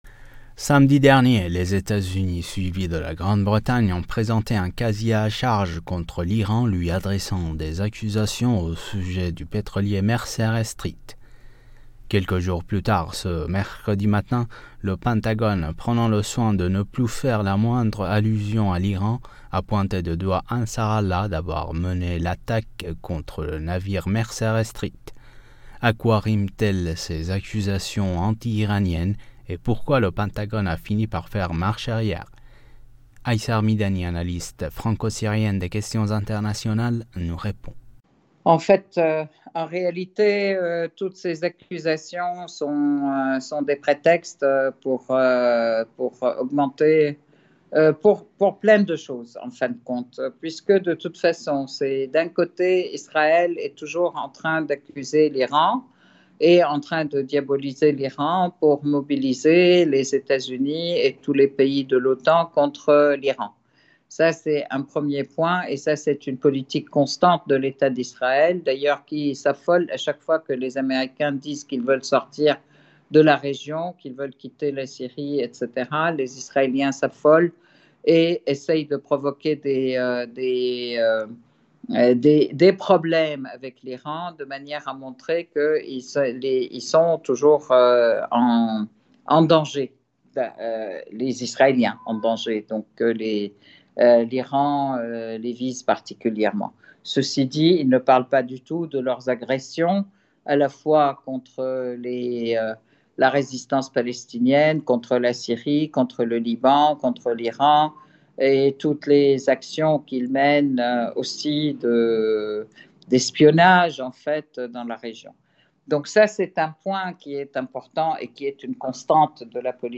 analyste franco-syrienne des questions internationales s'exprime sur le sujet.